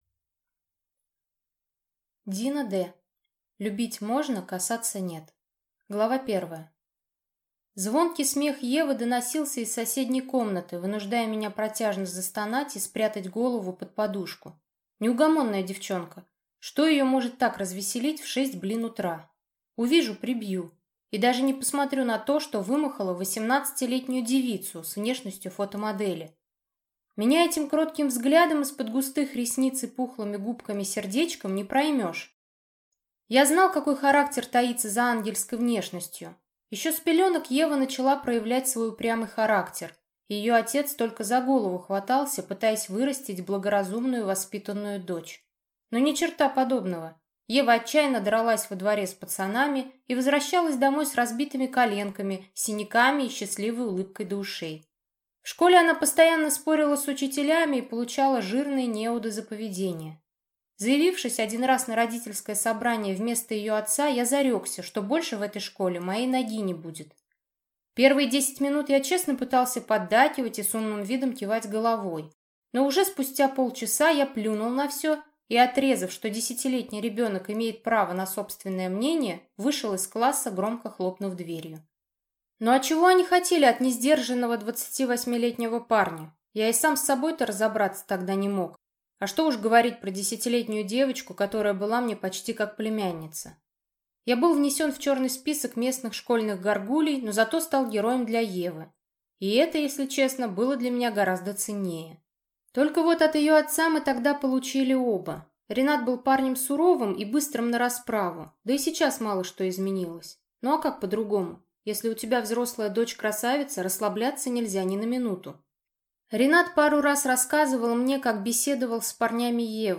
Аудиокнига Любить можно, Касаться нет | Библиотека аудиокниг